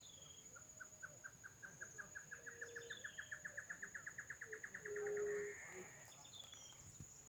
Choró-boi (Taraba major)
Nome em Inglês: Great Antshrike
Província / Departamento: Entre Ríos
Condição: Selvagem
Certeza: Observado, Gravado Vocal